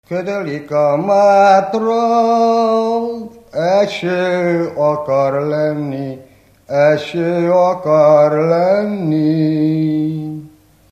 Felföld - Heves vm. - Karácsond
ének
Stílus: 1.1. Ereszkedő kvintváltó pentaton dallamok
Szótagszám: 6.6.6.6.6.6
Kadencia: 8 5 (5) 4 1 1